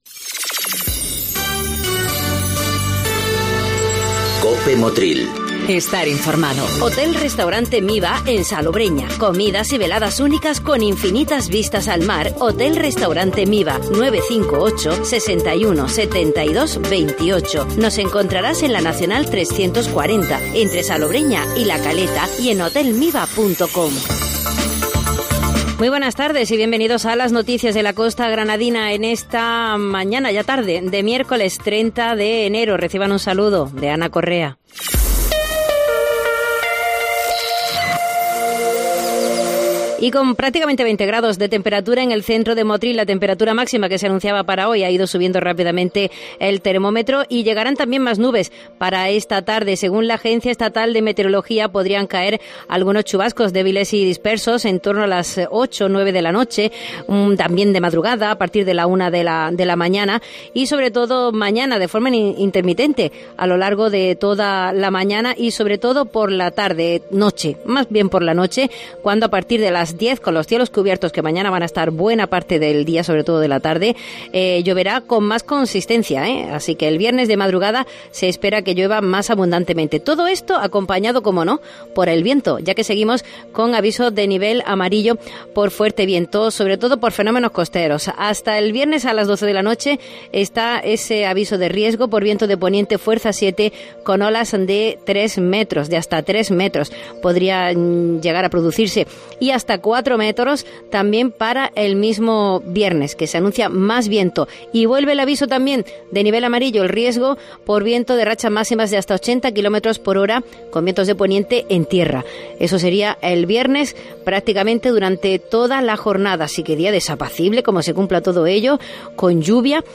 INFORMATIVO MEDIODÍA MIÉRCOLES 30 DE ENERO. ...Y llegaron las disculpas del diputado de turismo para la Costa